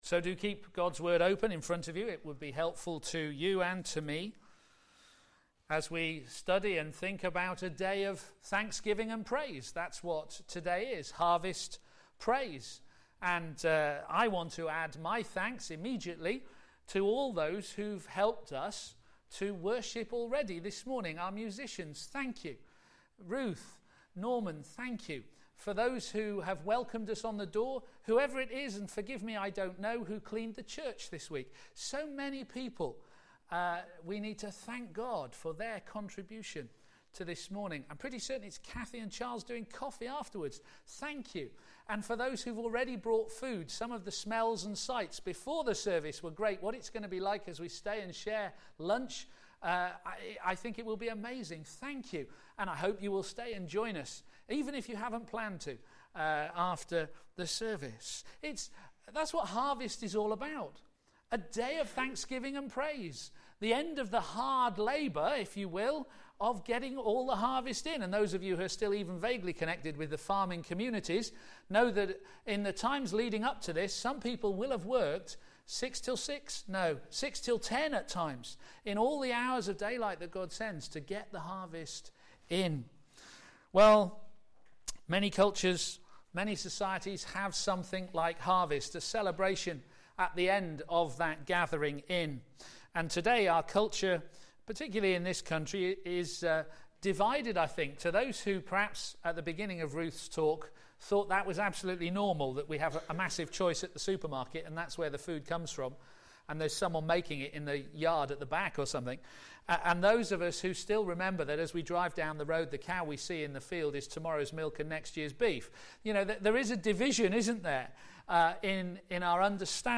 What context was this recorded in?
a.m. Service on Sun 22nd Sep 2013 10:30